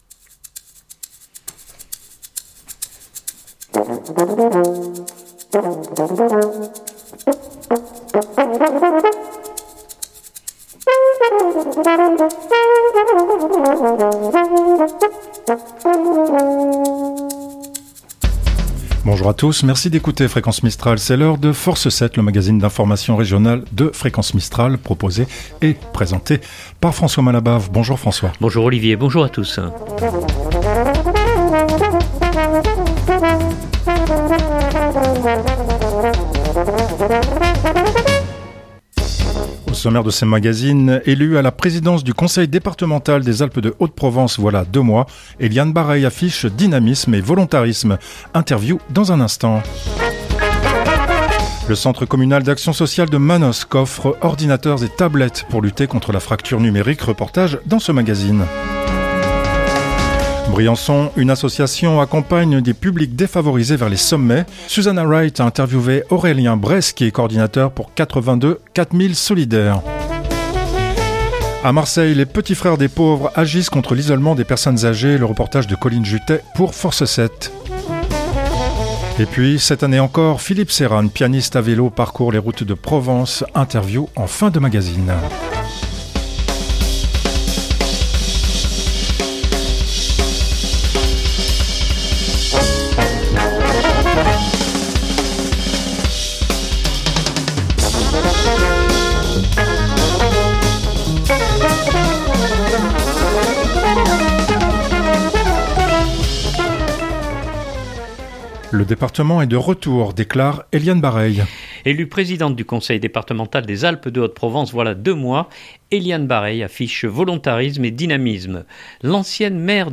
Interview dans un instant. Le centre communal d’action sociale de Manosque offre ordinateurs et tablettes pour lutter contre la fracture numérique. Reportage dans ce magazine. Briançon : une association accompagne des publics défavorisés vers les sommets.